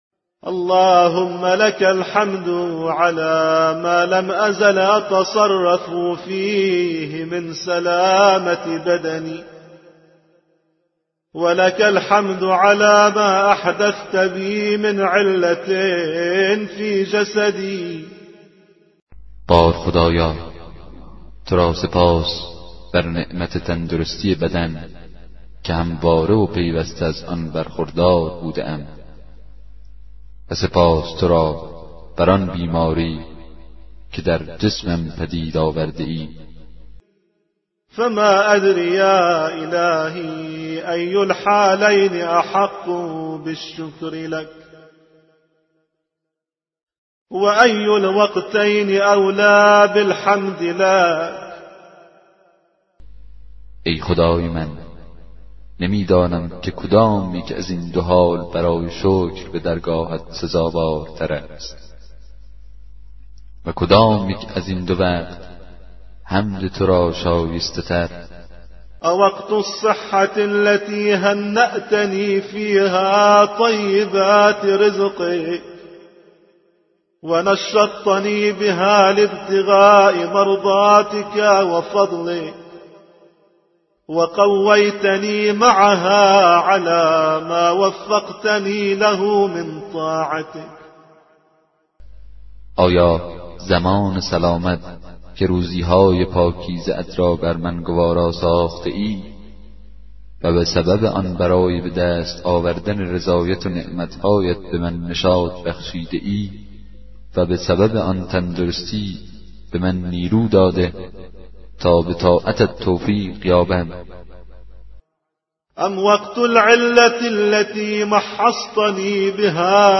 کتاب صوتی دعای 15 صحیفه سجادیه
به همراه ترجمه فارسی